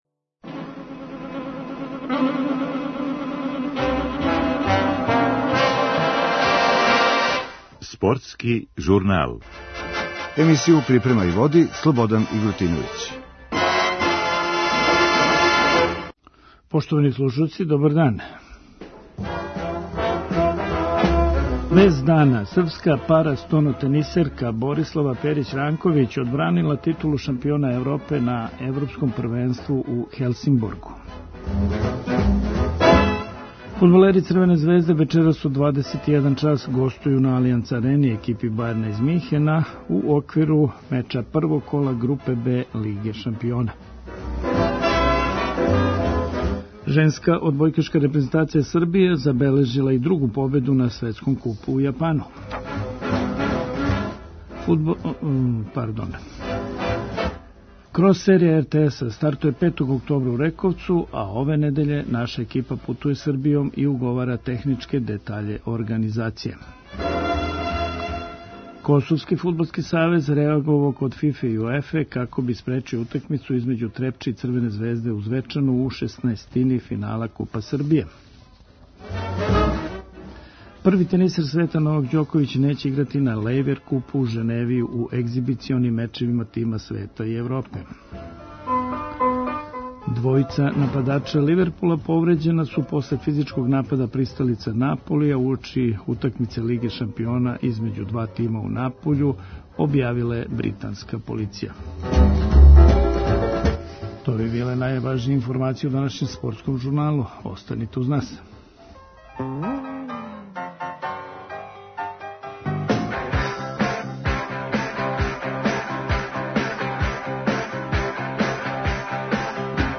Укључићемо нашег нашег репортера који ће преносити утакмицу вечерас.